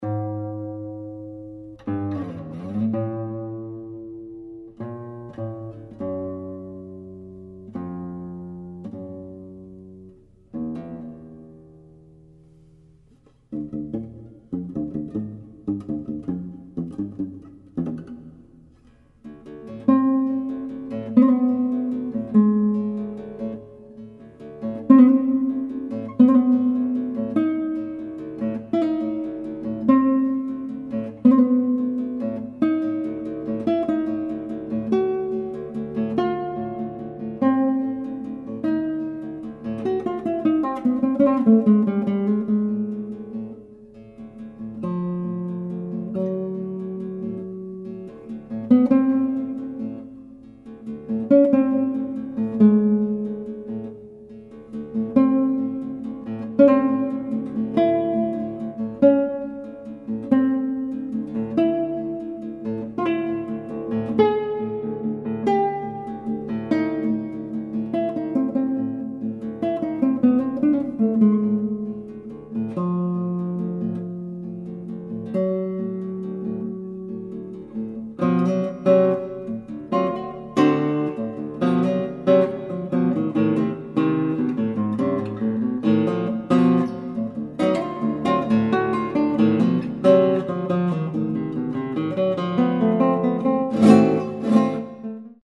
intimacies of color and liquid tone."